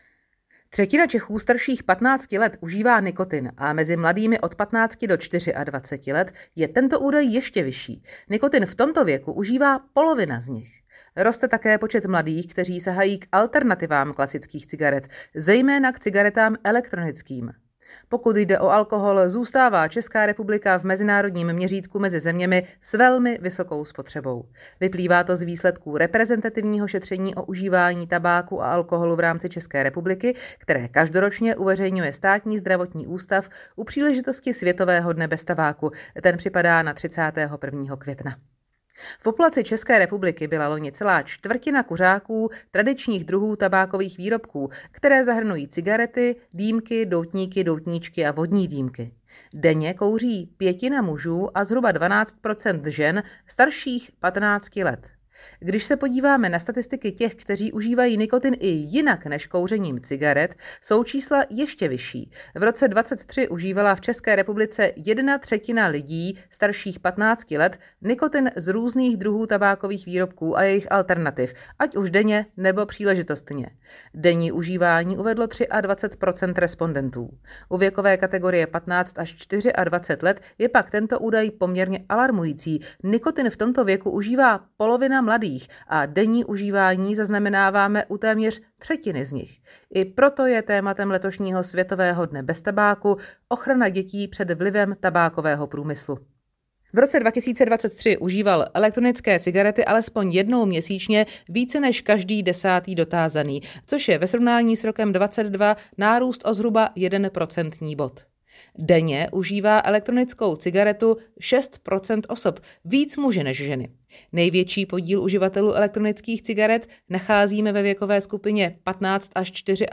Zvuk k tiskové zprávě NAUTA 23 Rozšířená tisková zpráva - grafy a komentáře